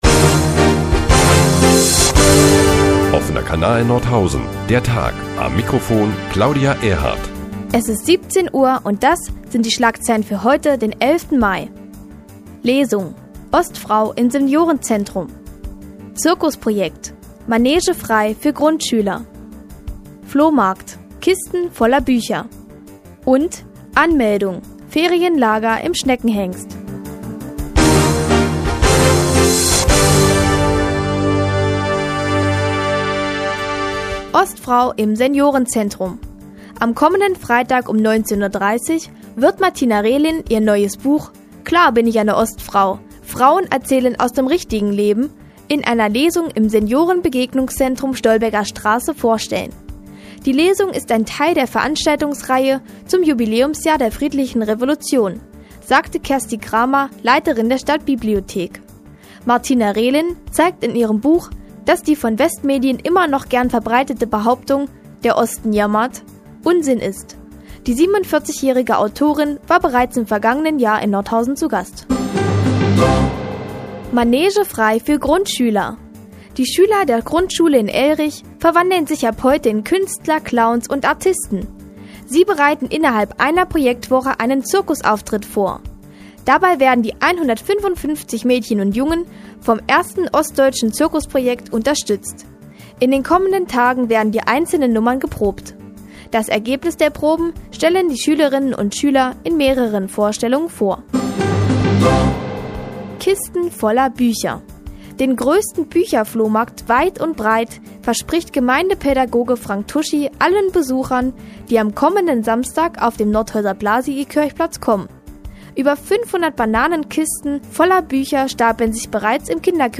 Die tägliche Nachrichtensendung des OKN ist nun auch in der nnz zu hören. Heute unter anderem mit dem größten Bücherflohmarkt in der Umgebung und Angebote für Ferienlager im Schullandheim Schneckenhengst für die Sommerferien.